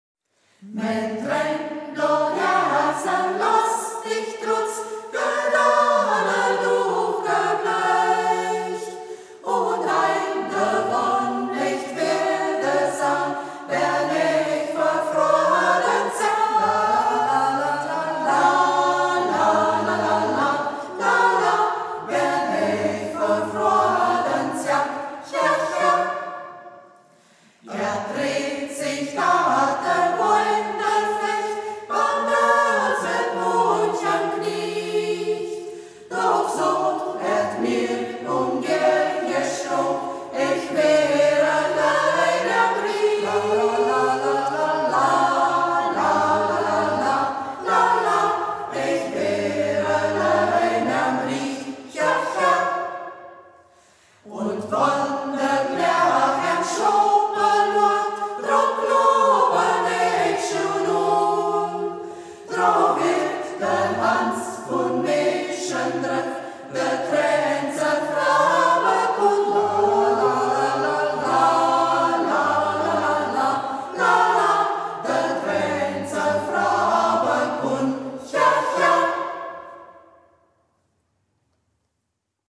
Ortsmundart: Braller